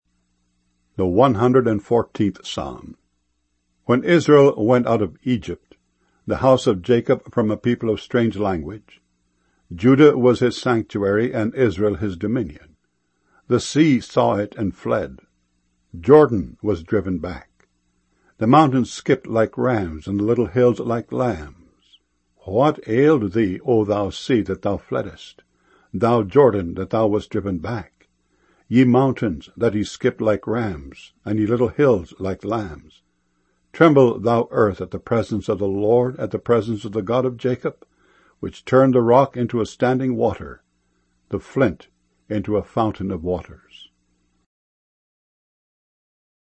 MP3 files mono 32 kbs small direct from wav files